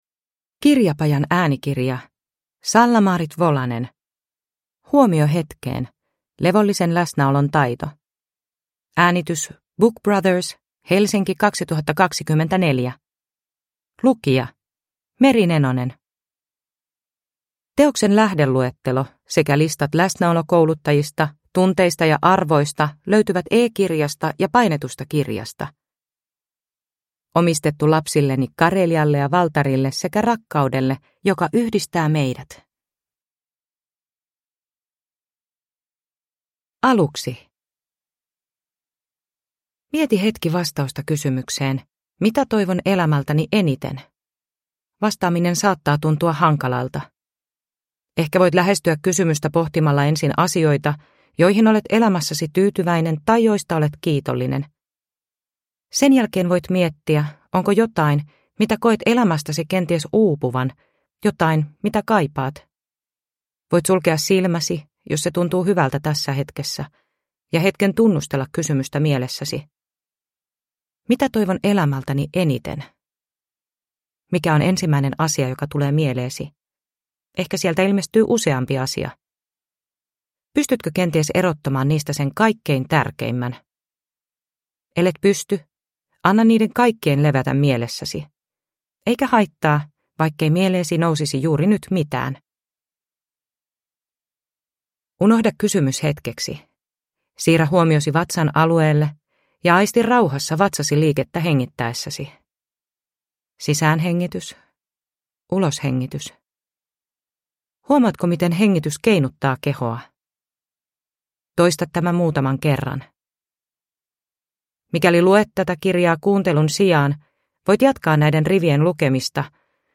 Huomio hetkeen – Ljudbok